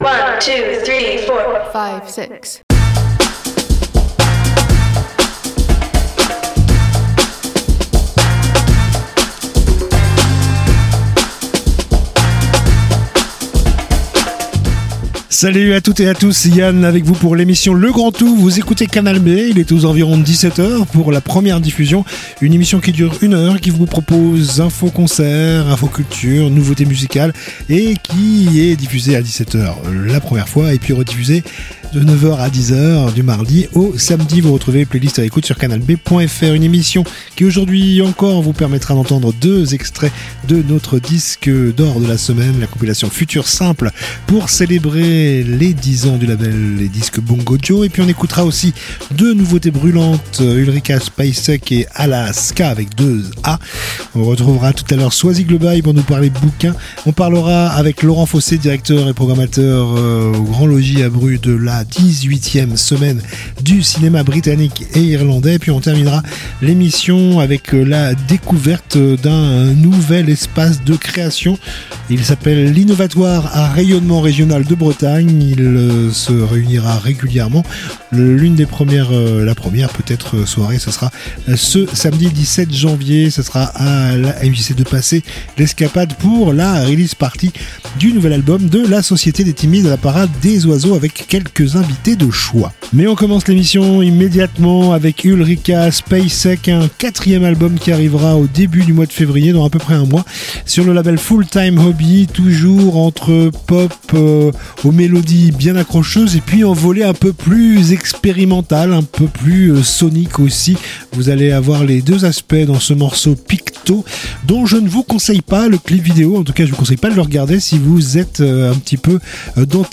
itv culture Discussion